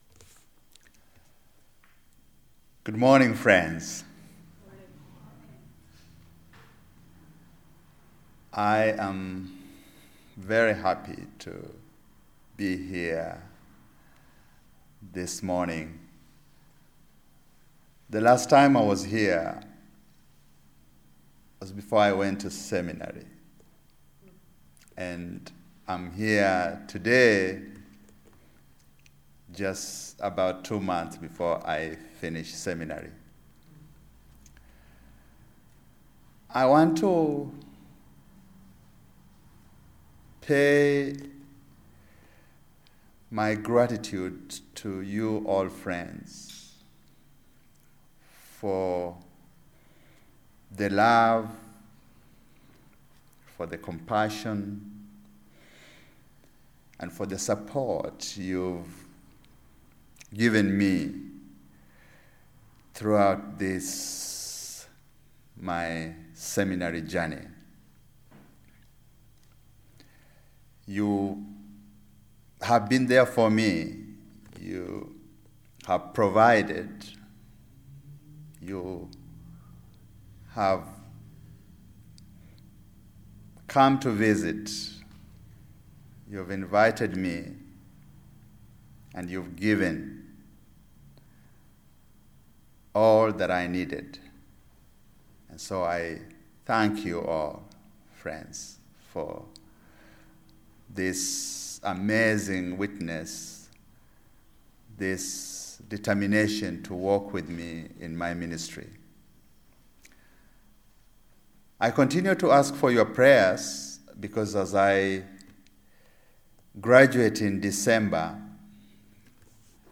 Listen to the most recent message, “What Stops Me,” from Sunday worship at Berkeley Friends Church.